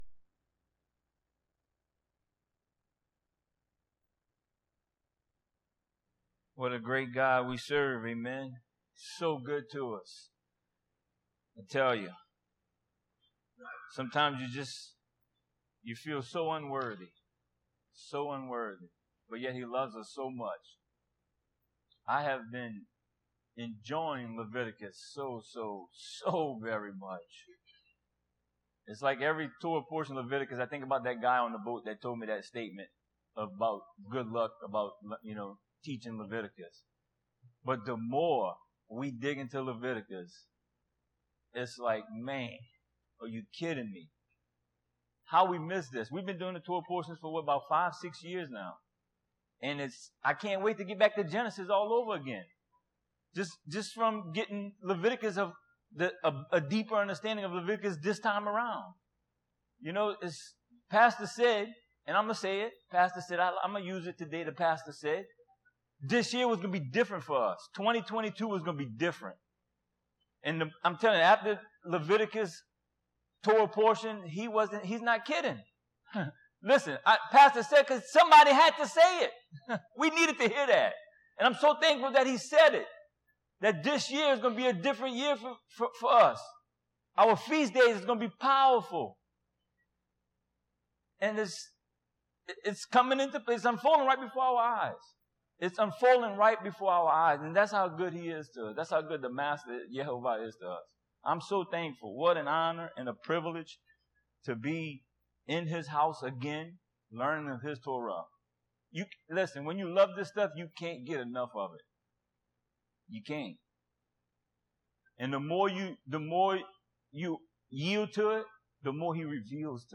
Torah Teachings – Metzora Part 2